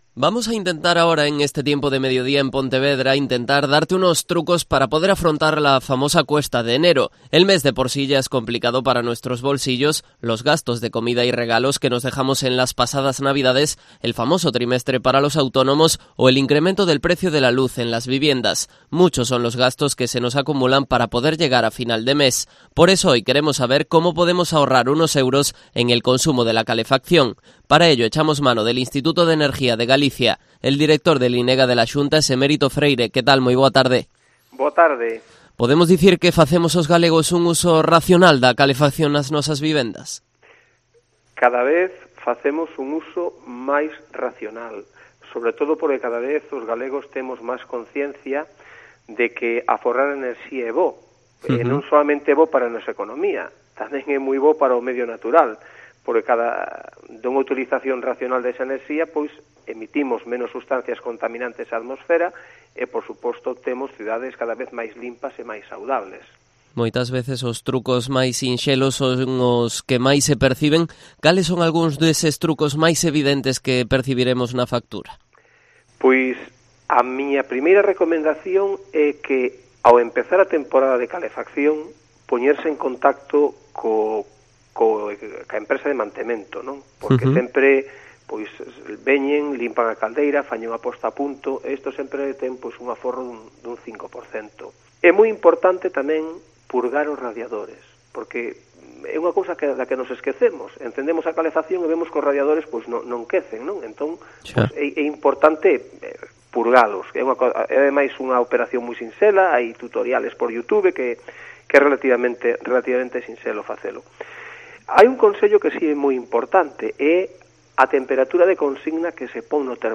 Entrevista de Cope Pontevedra al director del INEGA, Emérito Freire.